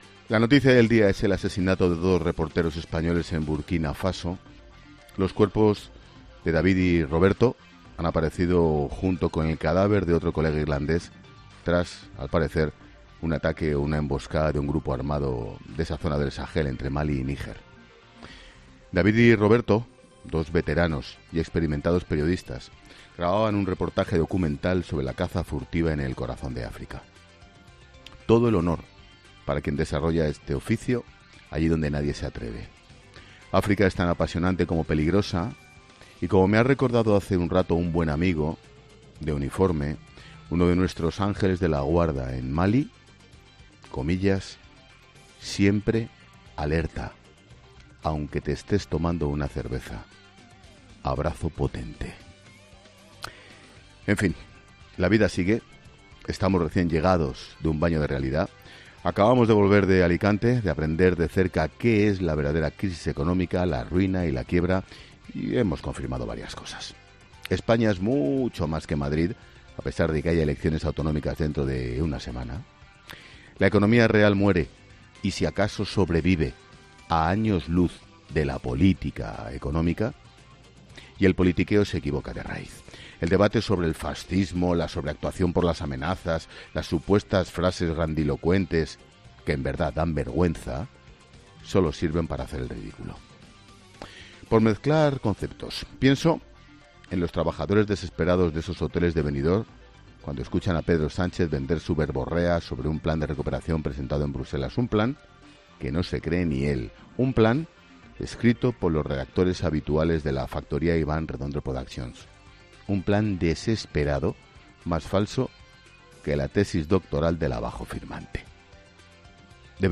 Monólogo de Expósito
El director de 'La Linterna', Ángel Expósito, analiza en su monólogo las principales claves informativas de este martes